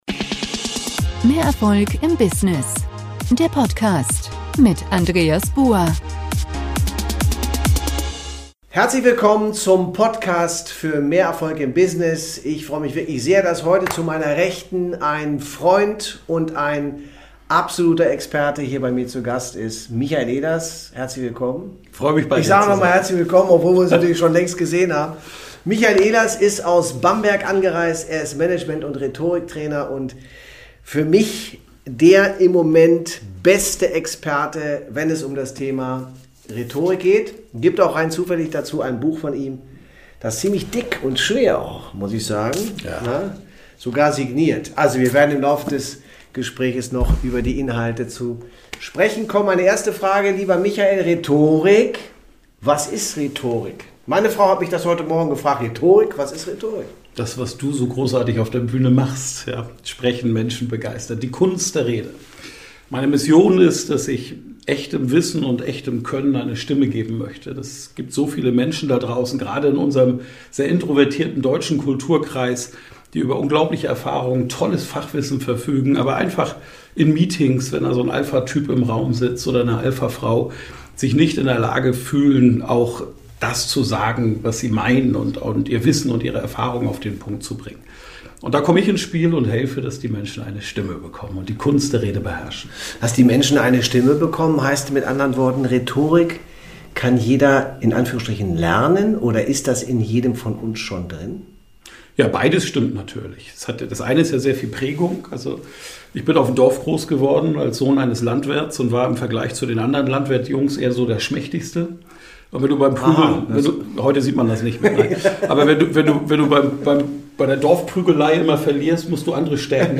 Rhetorik ERFOLG Unternehmertum VERKAUFEN Talk